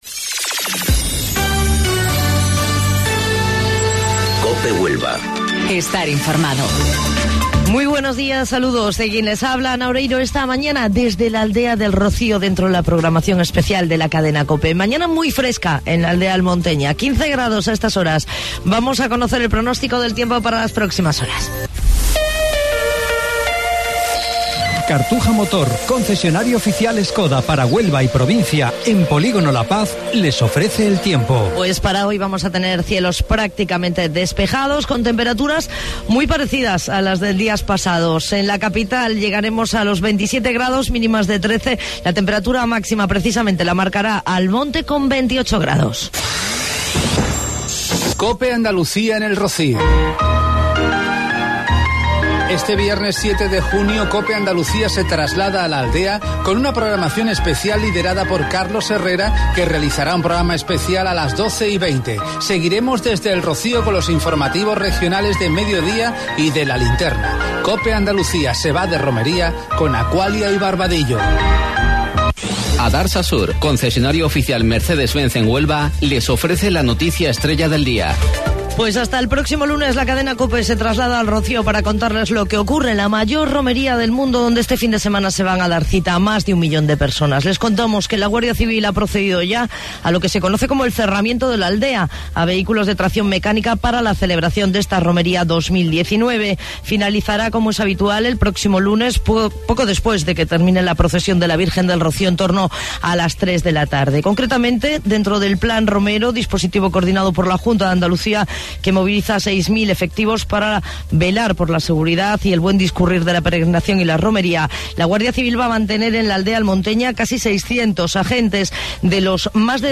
AUDIO: Informativo Local 07:55 del 7 de Junio